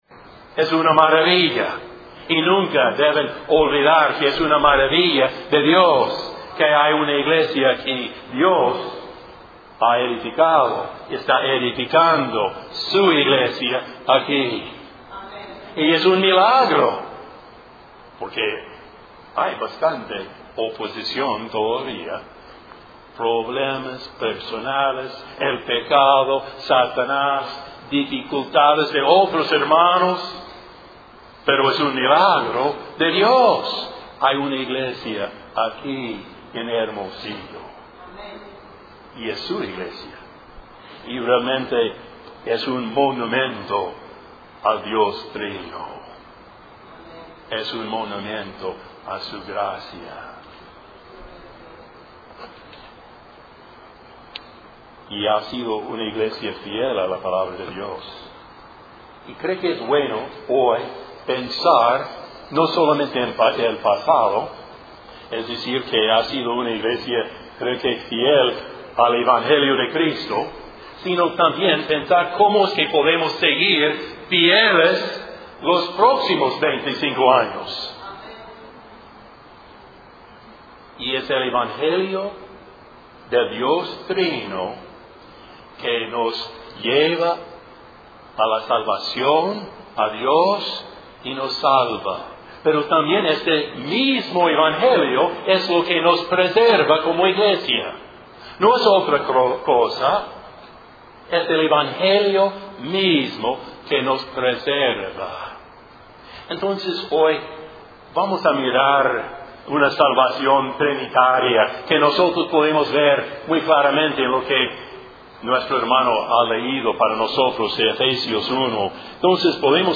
Serie de sermones General